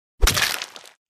Among Us Imposter Kill
Play Among Us Imposter Kill Sound Button For Your Meme Soundboard!